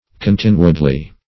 Continuedly \Con*tin"u*ed*ly\